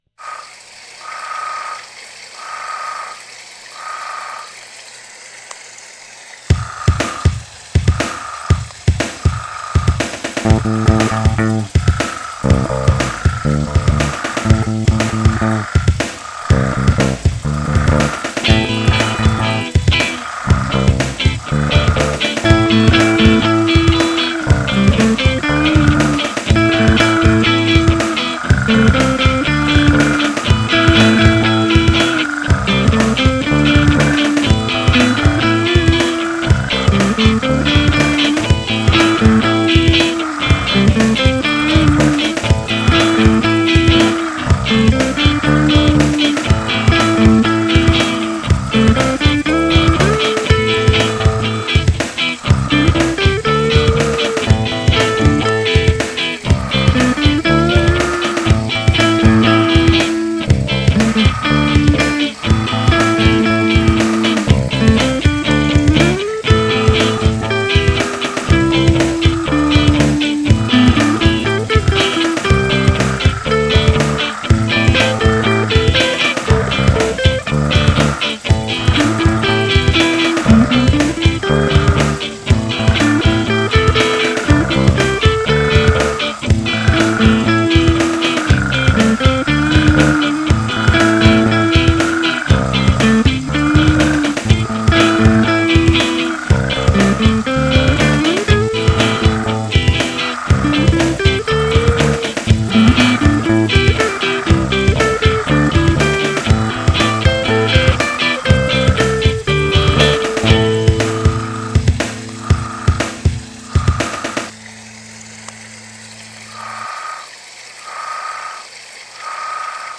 “Chorus of Toads”
The chorus of toads adds a rather unique froggy element to Froggyphunk.